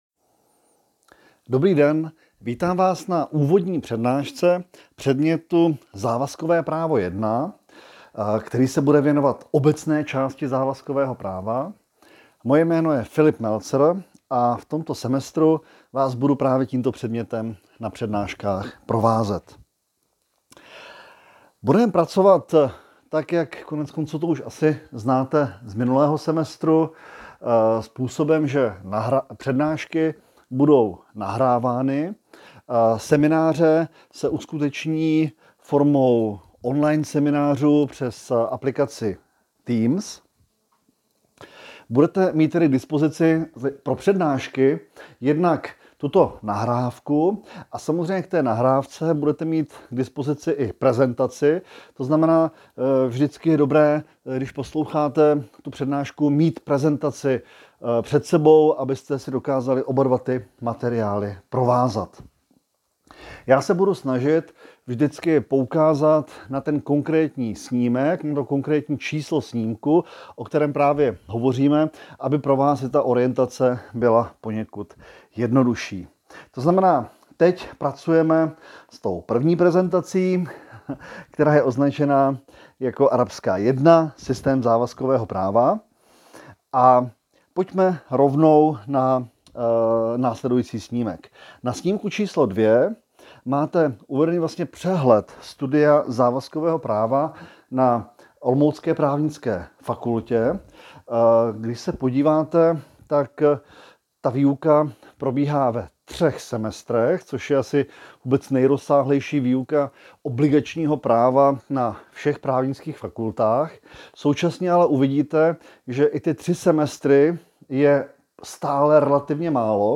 MZav_prednaska1_1.cast.mp3